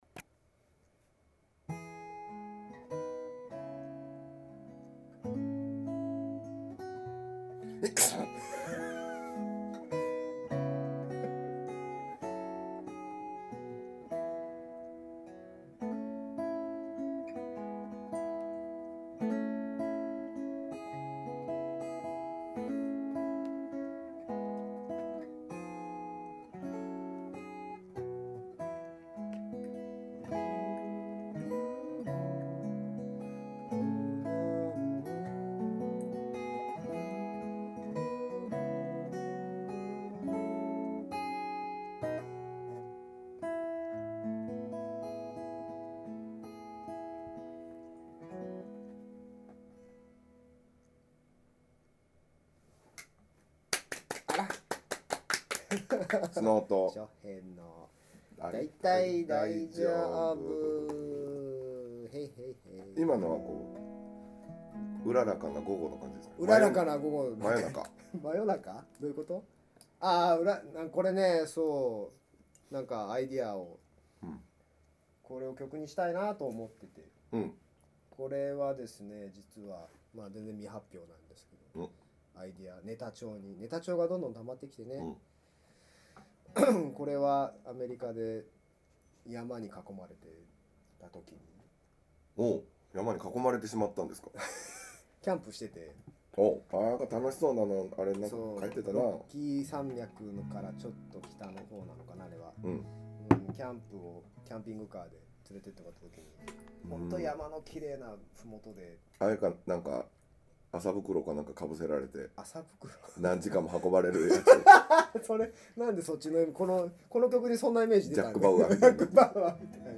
Episode 56 家でのまったりトークもいいですね、コーヒーが美味しいです。